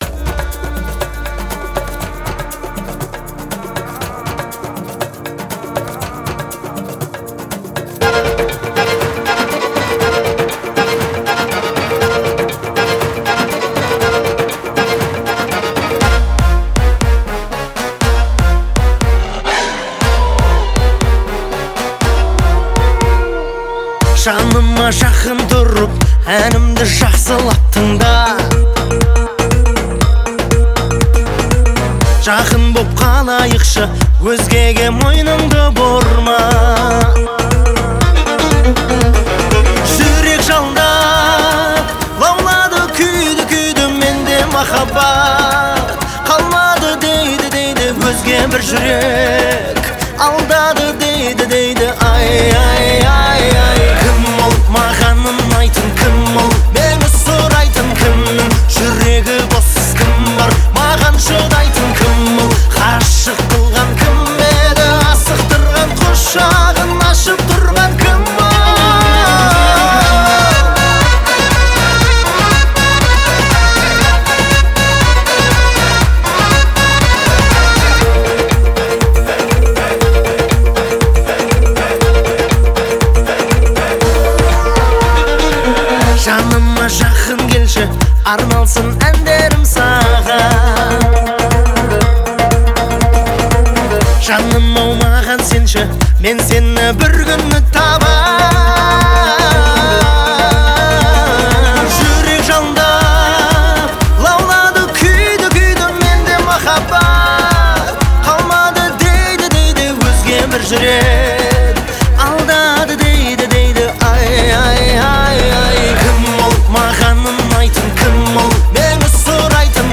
это яркая композиция в жанре казахской поп-музыки